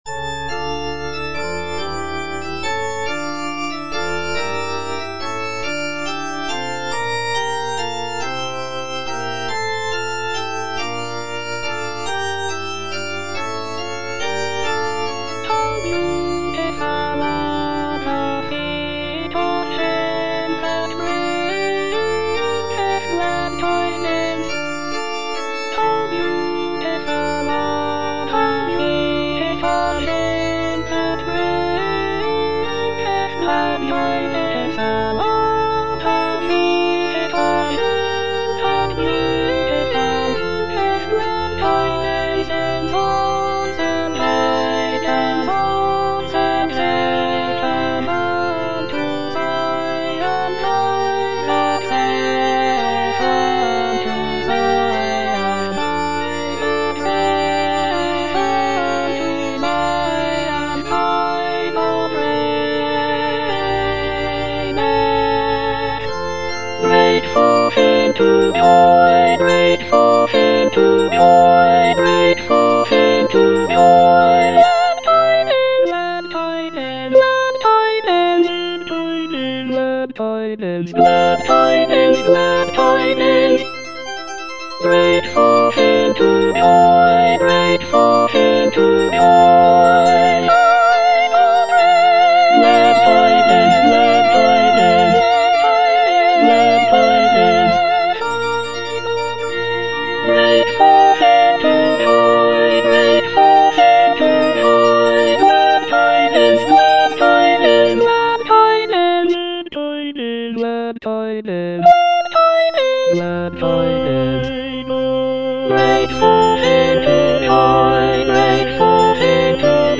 G.F. HÄNDEL - HOW BEAUTIFUL ARE THE FEET OF HIM FROM "MESSIAH" (DUBLIN 1742 VERSION) Soprano (Emphasised voice and other voices) Ads stop: